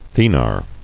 [the·nar]